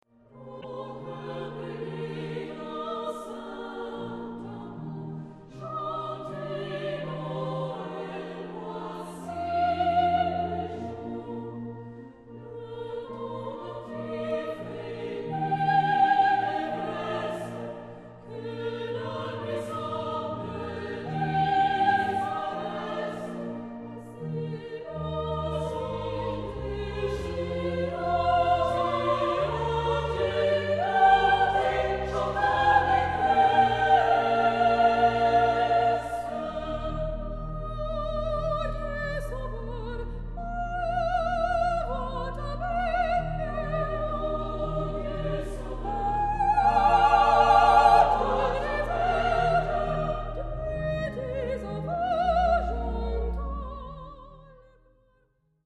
Tipo de formación coral: SSA  (3 voces Coro femenino )
Solistas : Soprano (1) / Alto (1)  (2 solista(s) )
Instrumentos: Piano (1) ; Organo (ad lib)
Tonalidad : mi bemol mayor